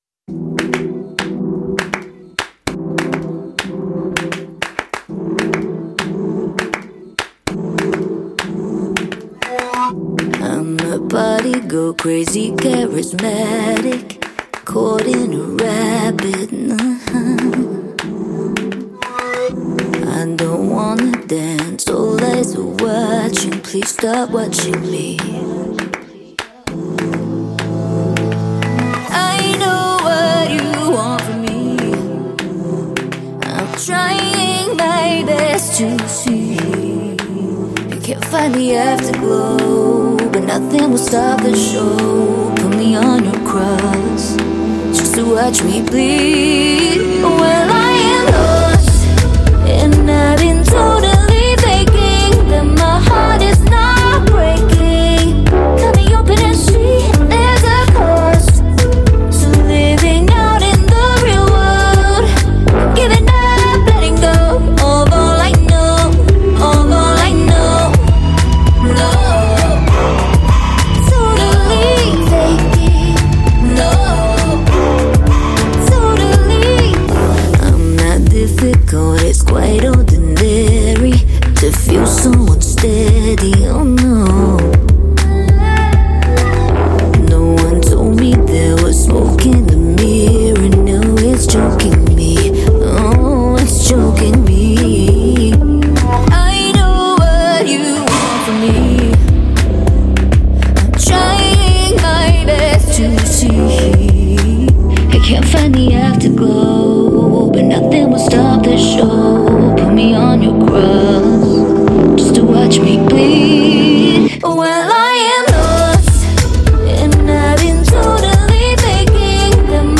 # Electronic # Electronic Pop # Pp # Dance # Dance Pop